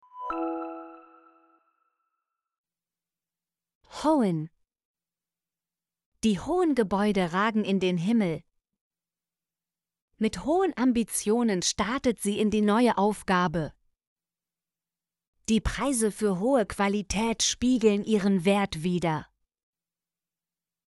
hohen - Example Sentences & Pronunciation, German Frequency List